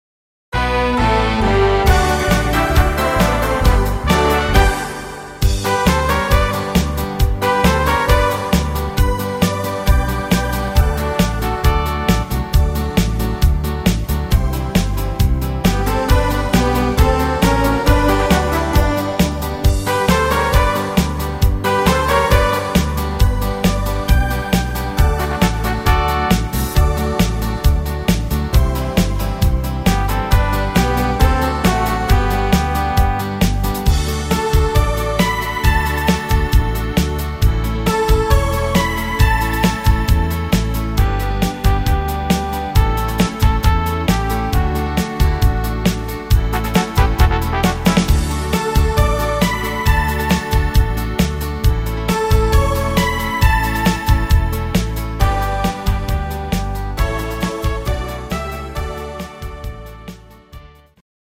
Rhythmus  Beat Marsch
Art  Deutsch, Volkstümlich, Party Hits